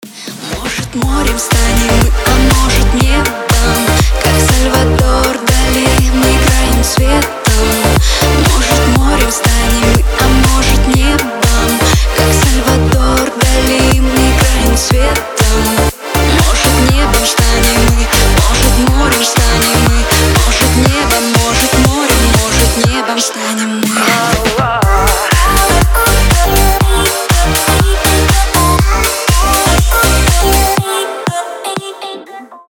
• Качество: 320, Stereo
поп
громкие
заводные